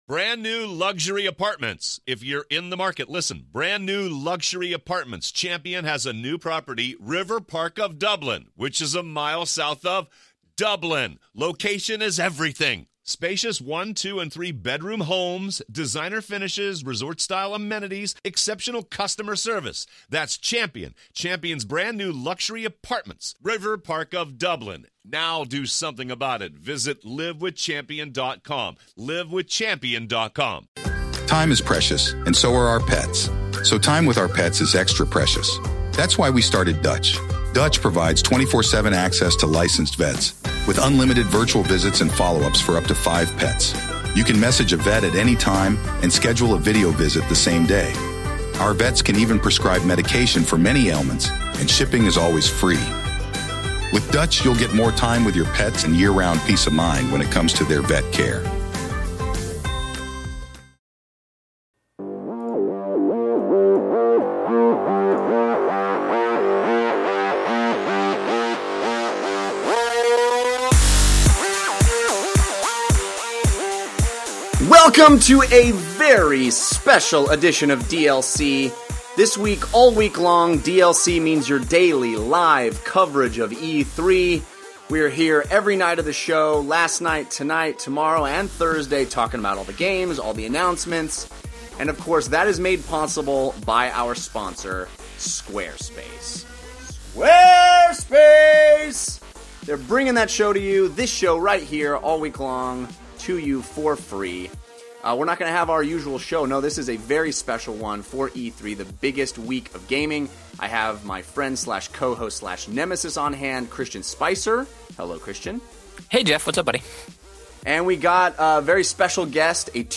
the second live E3 episode of 2014. This, the first official day of the conference, started with Nintendo's big Nintendo Direct announcements, and continued with great games.
YOUR phone calls